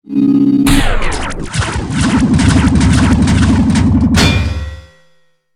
dig.ogg